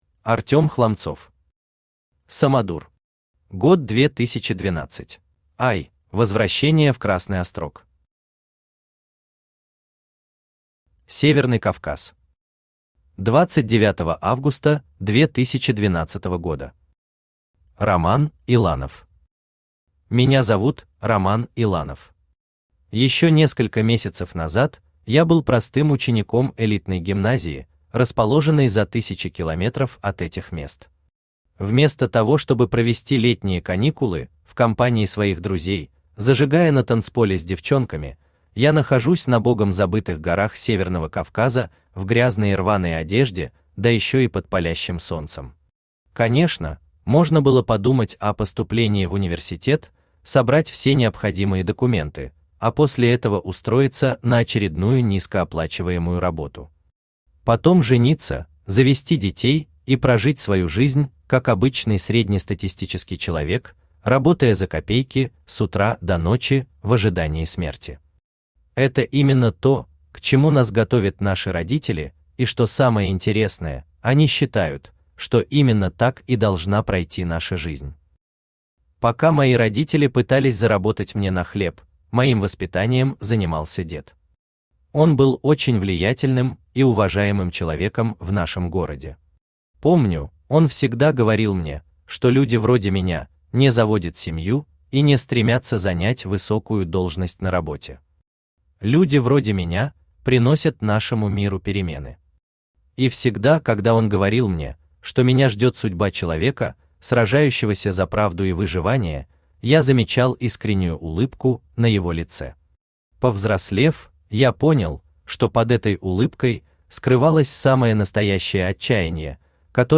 Аудиокнига Самодур, год 2012 | Библиотека аудиокниг
Aудиокнига Самодур, год 2012 Автор Артём Денисович Хламцов Читает аудиокнигу Авточтец ЛитРес.